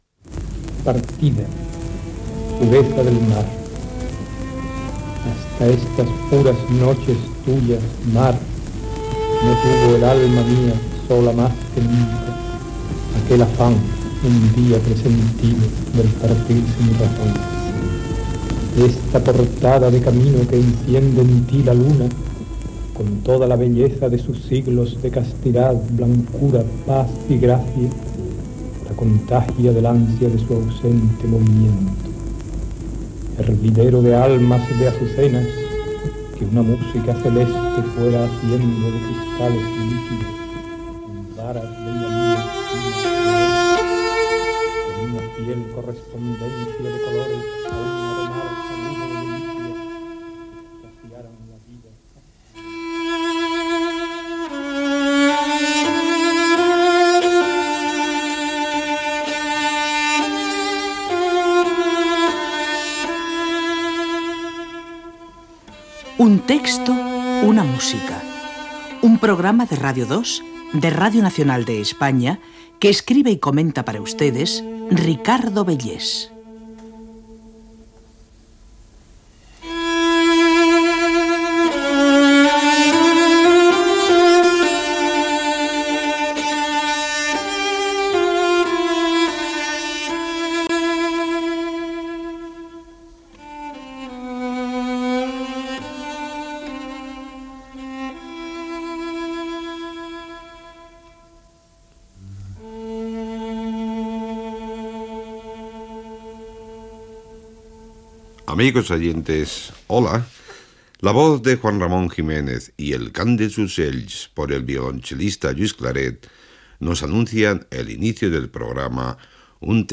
1a5187b34a68c5ef3c74ba9ed156b6b891182a9e.mp3 Títol Radio 2 Emissora Radio 2 Cadena RNE Titularitat Pública estatal Nom programa Un texto una música Descripció El poeta Juan Ramón Jiménez recita un poema acompanyat de la música de "El cant dels ocells". Careta del programa.